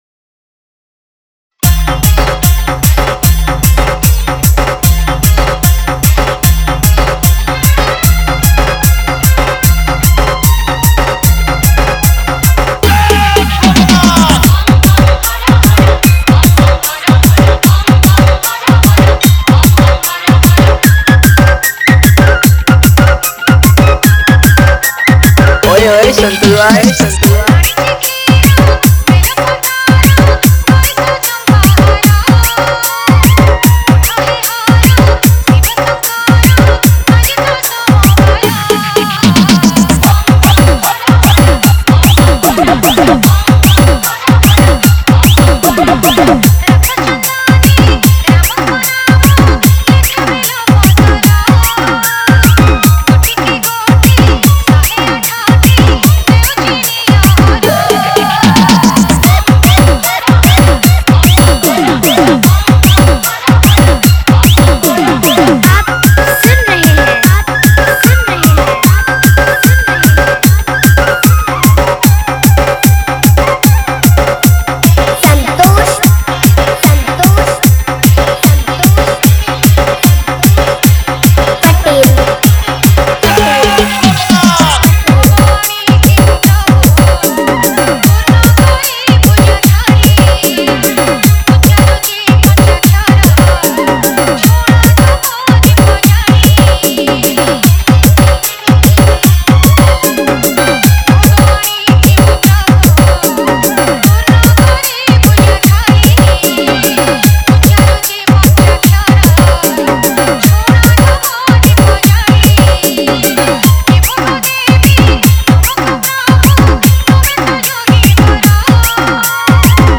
Shivratri Special DJ Remix Songs